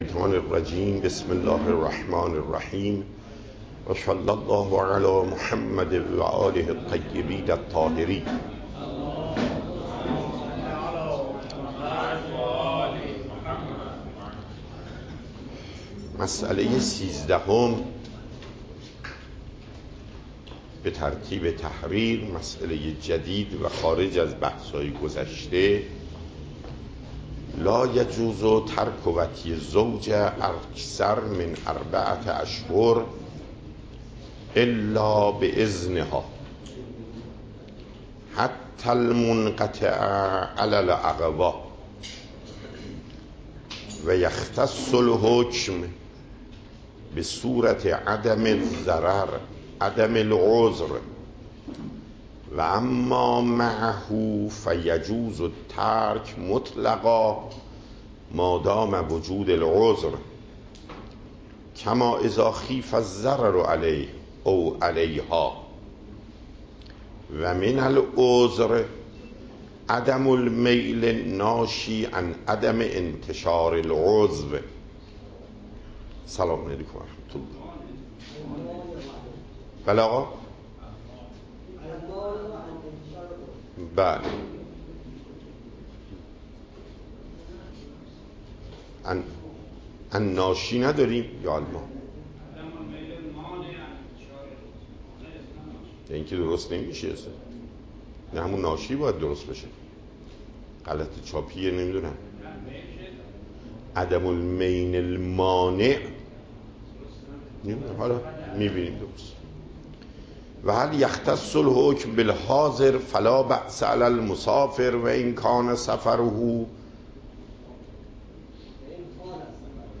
صوت درس
درس فقه آیت الله محقق داماد